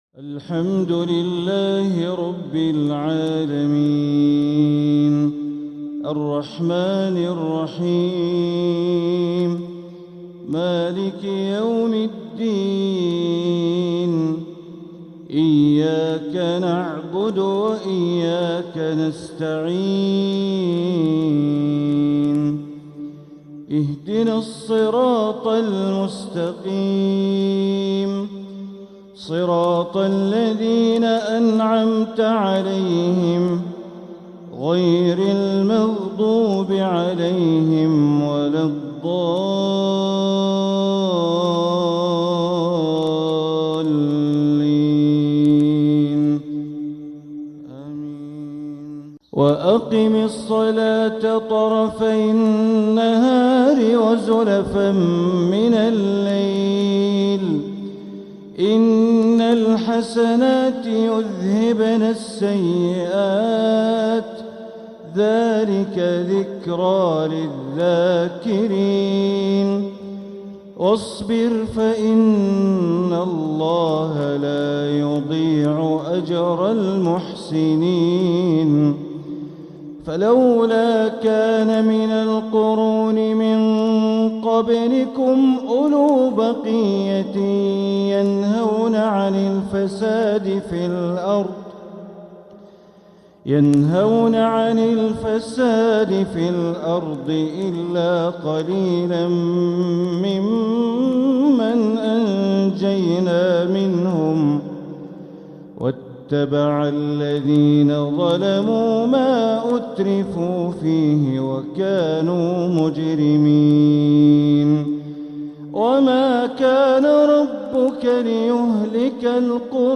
سلسلة اللآلئ الأصيلة لتلاوات الشيخ بندر بليلة لتلاوات شهر صفر وربيع الأول ١٤٤٧هـ ( التاسعة والسبعون) > سلسلة اللآلئ الأصيلة لتلاوات الشيخ بندر بليلة > المزيد - تلاوات بندر بليلة